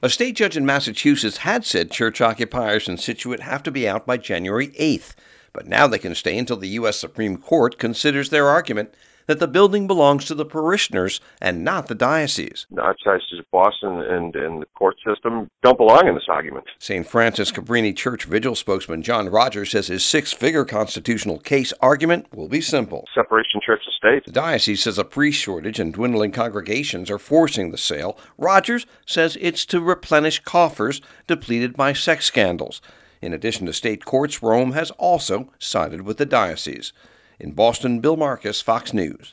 (BOSTON) DEC 22 – A GROUP THAT FOR 11 YEARS HAS BEEN OCCUPYING A CHURCH CLOSED BY THE BOSTON ARCHDIOCESE IS MAKING IT’S FINAL PLEA – TO THE U-S SUPREME COURT. FOX NEWS RADIO’S